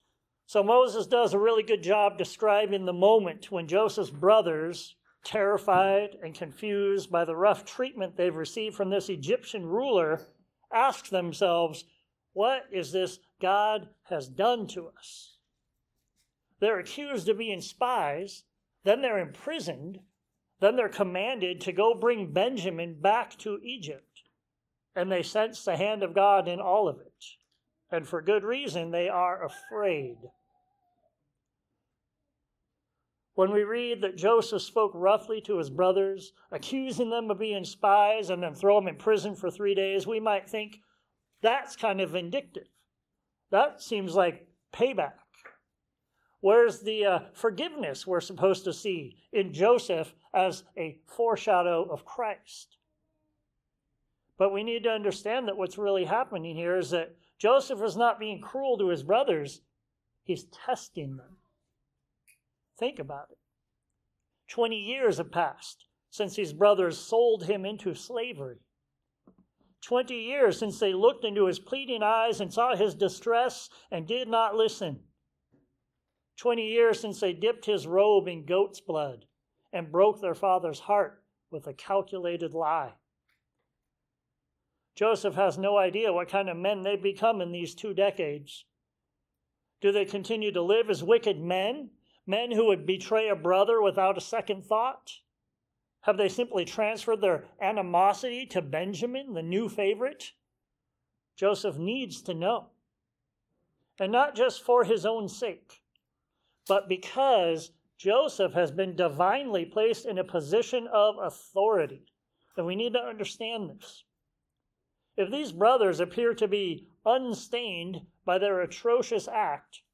Genesis 42:6-43:15 What God Hath Wrought Sermons Share this: Share on X (Opens in new window) X Share on Facebook (Opens in new window) Facebook Like Loading...